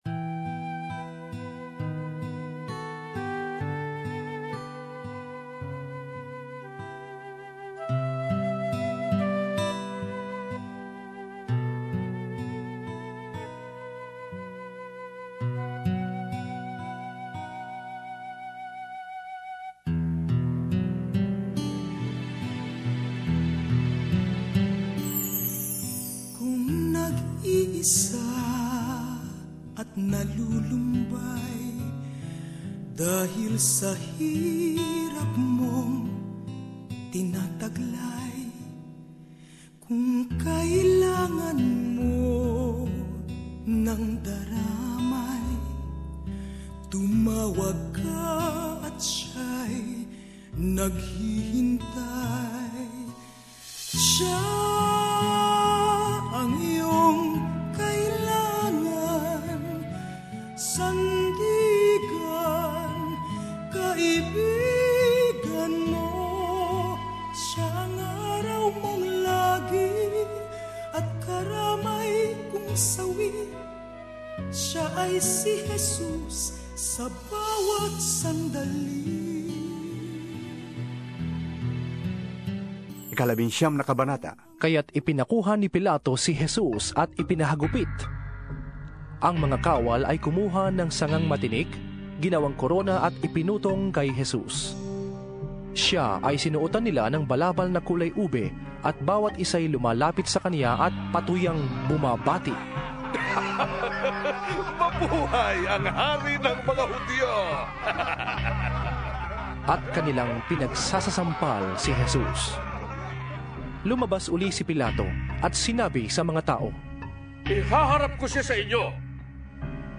Image: Jesus on the cross (SBS) Here is the first part of a dramatisation of the last hours in the life of our Saviour, as presented by the Philippine Bible Society.